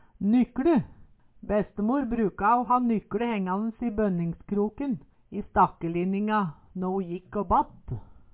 nykLe - Numedalsmål (en-US)